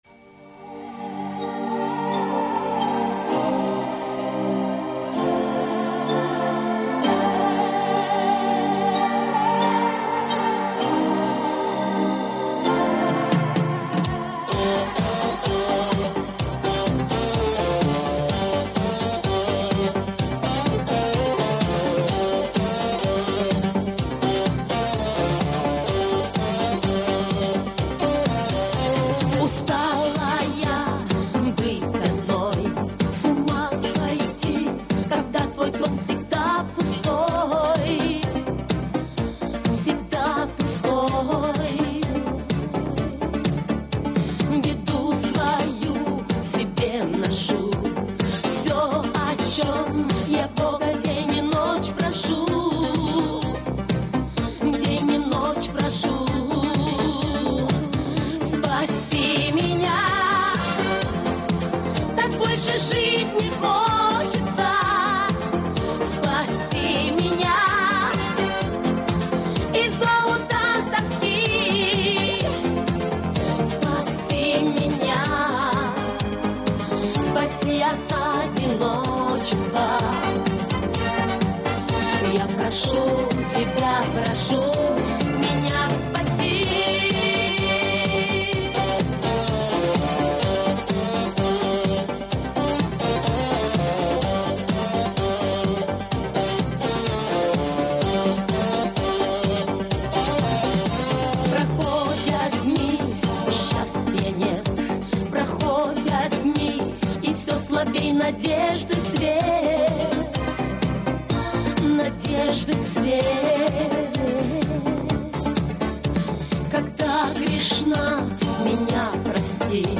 Щас...По-моему это единственная запись из зала